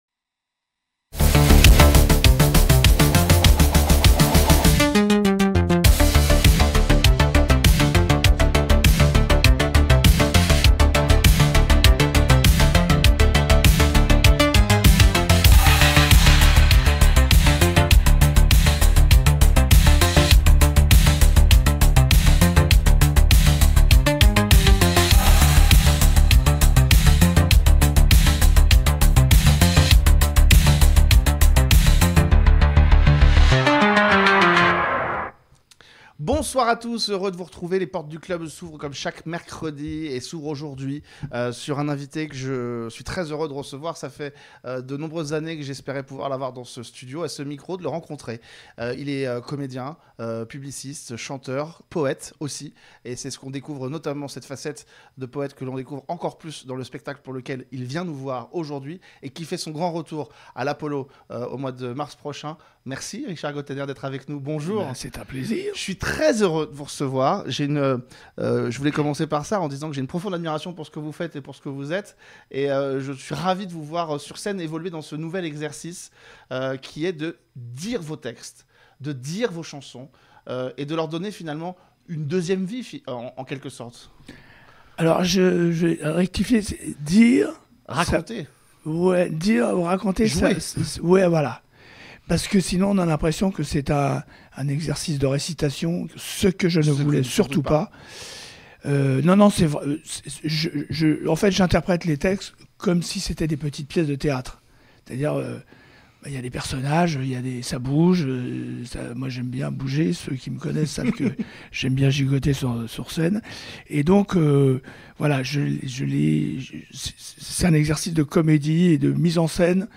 L’invité : Richard Gotainer